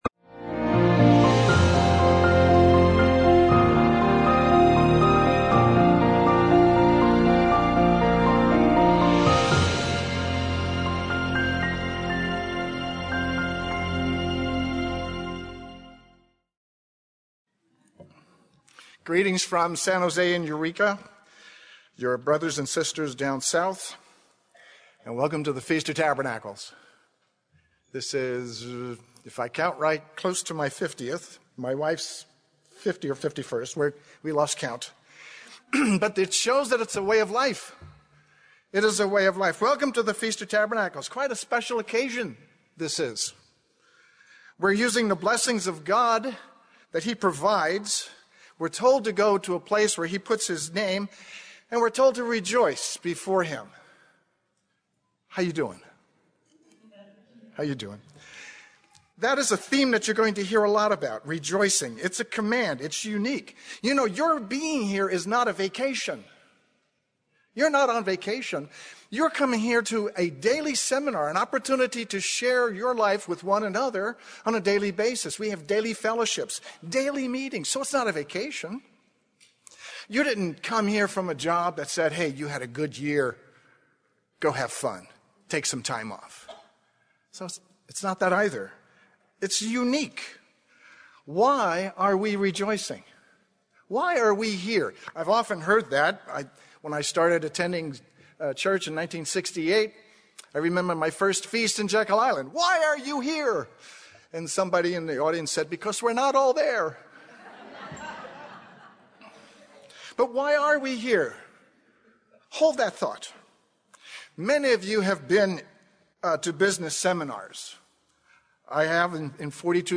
This sermon was given at the Bend-Redmond, Oregon 2017 Feast site.